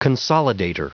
Prononciation du mot consolidator en anglais (fichier audio)
Prononciation du mot : consolidator